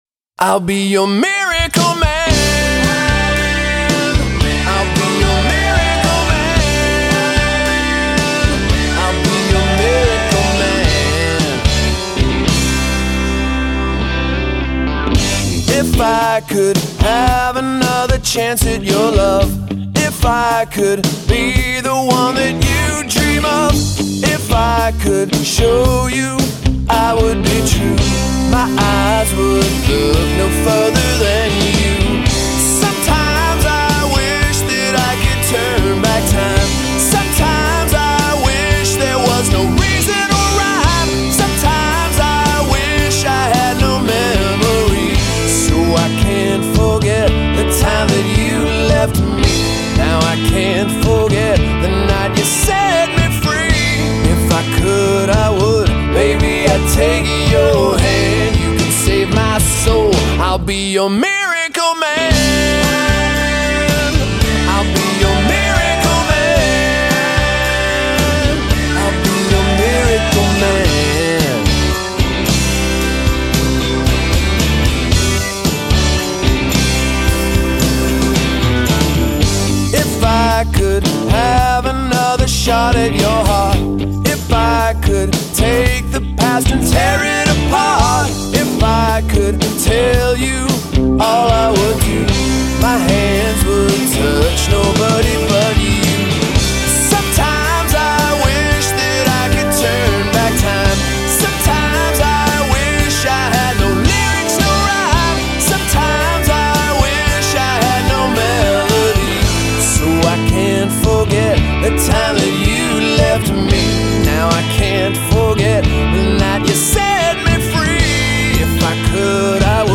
rock band
soaring vocals